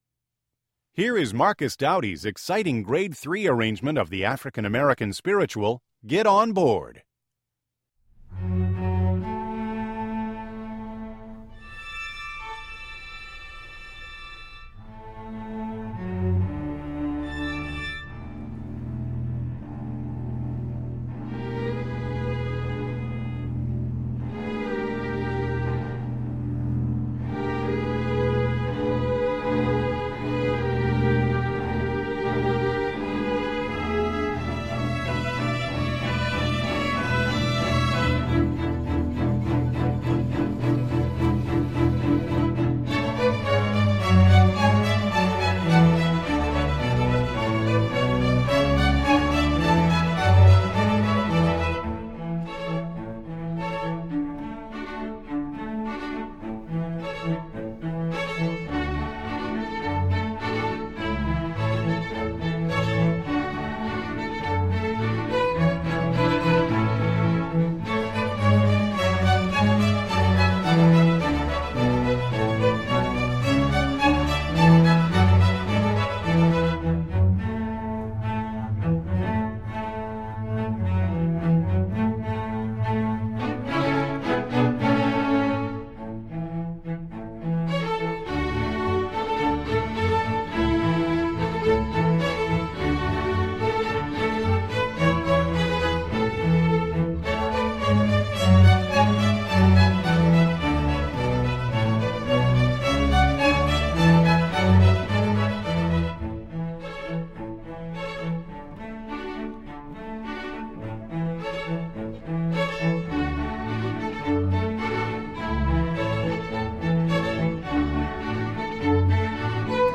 Composer: Spiritual
Voicing: String Orchestra